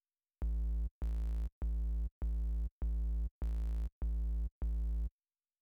interesting! i just tried here and Trk 2’s osc sounds much buzzier at that low note.
here’s an audio snip of voices – 1, 2, 3, 4, 1, 2, 3, 4.